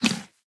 Minecraft Version Minecraft Version 1.21.5 Latest Release | Latest Snapshot 1.21.5 / assets / minecraft / sounds / mob / mooshroom / eat3.ogg Compare With Compare With Latest Release | Latest Snapshot
eat3.ogg